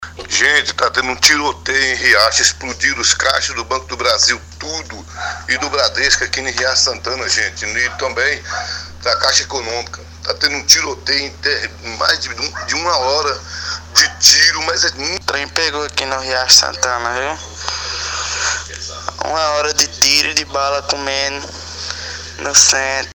Ouçam relatos dos moradores:
relatos-de-Morador.mp3